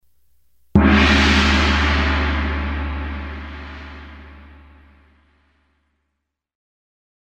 Глубокие вибрации и переливчатые обертоны подойдут для медитации, звукотерапии или создания атмосферы в творческих проектах.
Звук мощного удара по классическому гонгу для аудиомонтажа